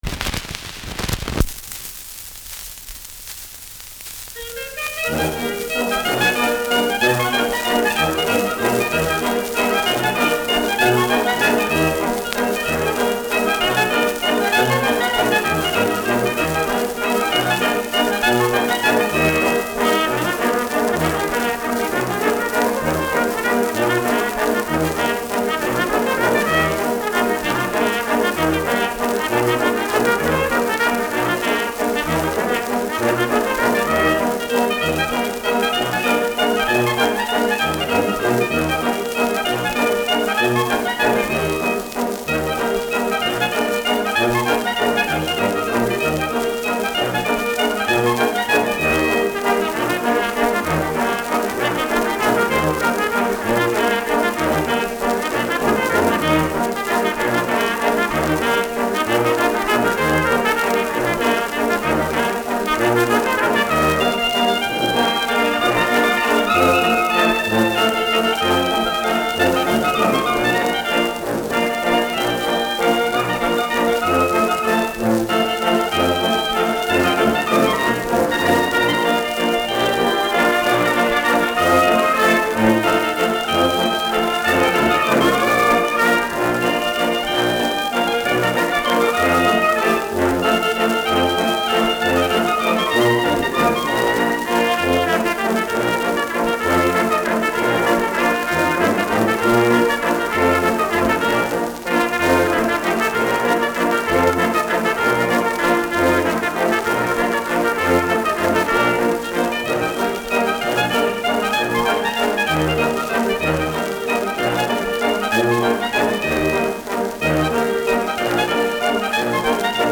Schellackplatte
Leichtes Leiern : Vereinzelt leichtes Knacken
[München] (Aufnahmeort)